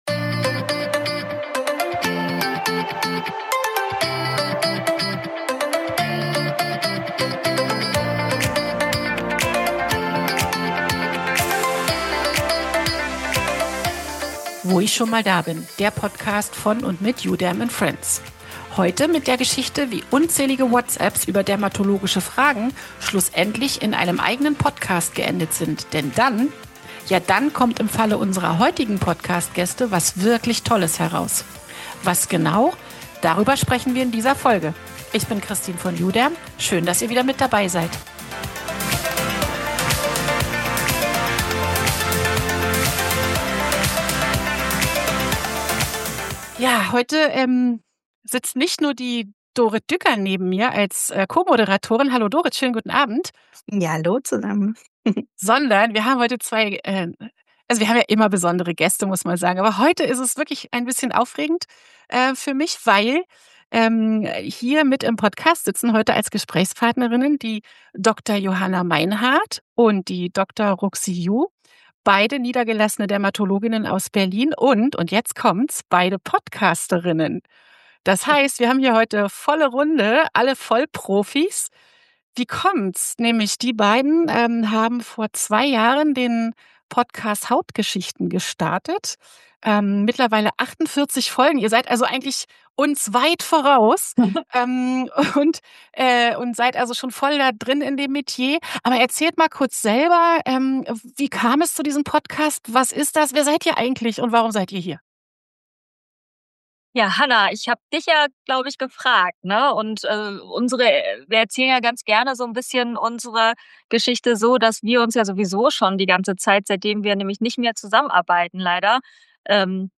Am Ende wurde es eine Folge voller Humor, Klartext und Hautwissen, das man sonst nur bekommt, wenn man Dermatologinnen heimlich im Kinderzimmer beim Podcasten belauscht.